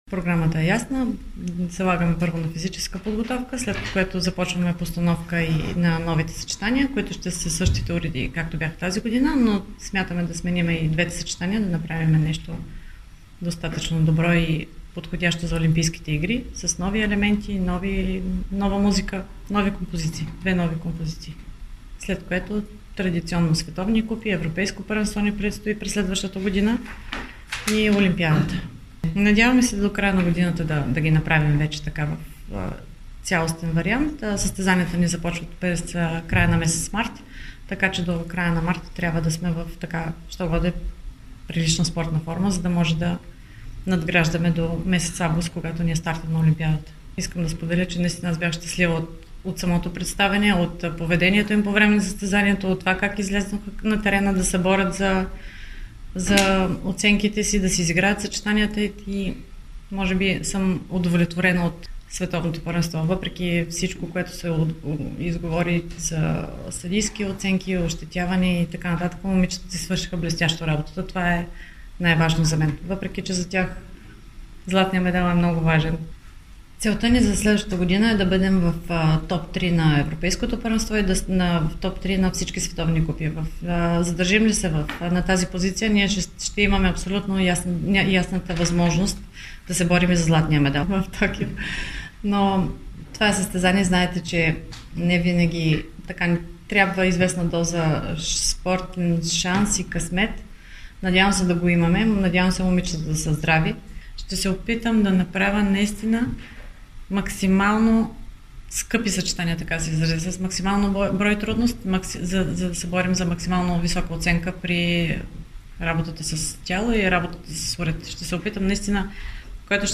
Гимнастичките и Димитрова бяха наградени за отбор и треньор на месец септември и на брифинга с медиите разкриха каква е програмата им за предстоящата 2020 година.